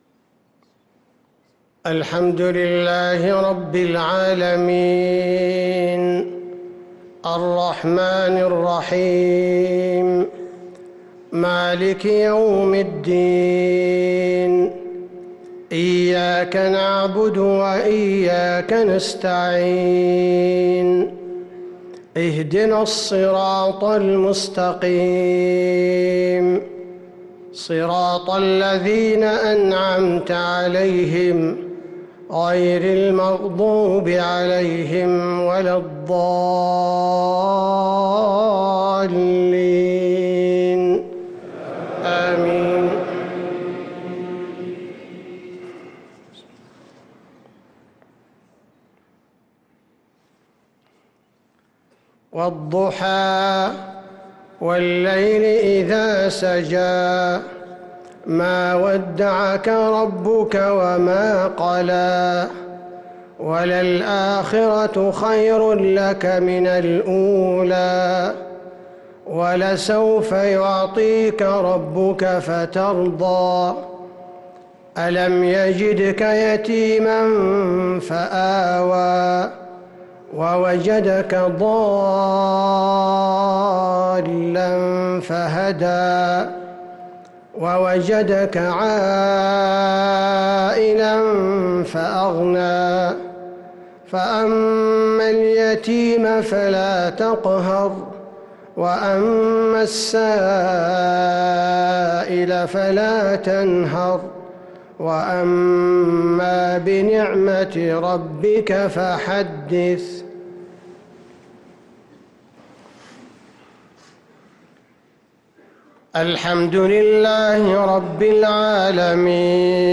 صلاة العشاء للقارئ عبدالباري الثبيتي 16 شعبان 1445 هـ
تِلَاوَات الْحَرَمَيْن .